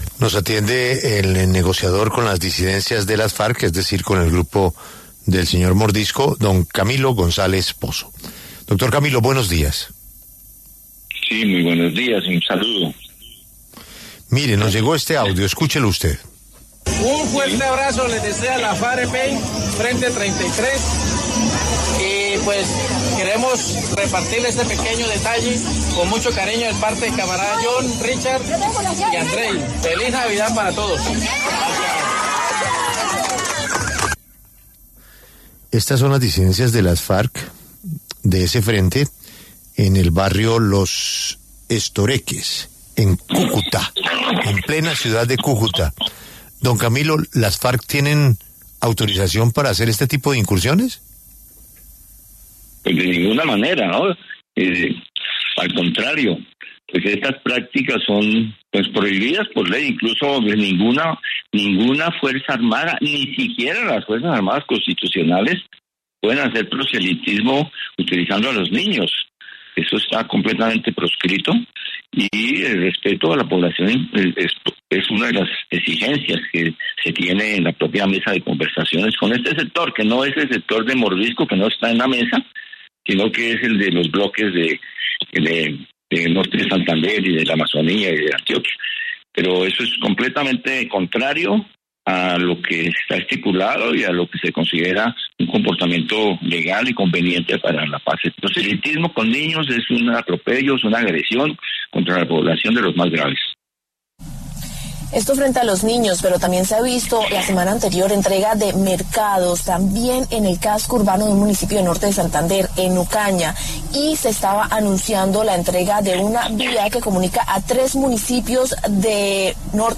Ante los micrófonos de La W estuvo el jefe negociador con el Estado Mayor Central de las Farc, Camilo González Posso, y señaló que estas prácticas están prohibidas por ley.